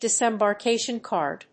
アクセントdisembarkátion càrd